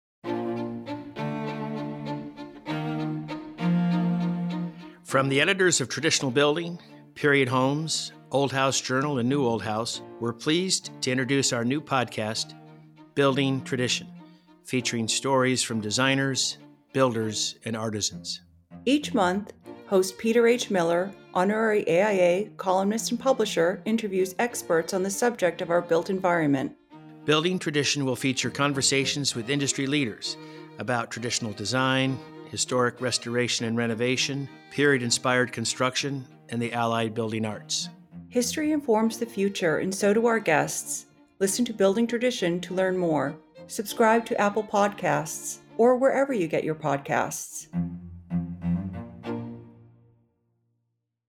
From the editors of TRADITIONAL BUILDING, PERIOD HOMES DIGITAL, OLD HOUSE JOURNAL, and NEW OLD HOUSE, Building Tradition is a new podcast featuring interviews with industry leaders about traditional design, historic restoration/renovation; period inspired construction and the allied building arts.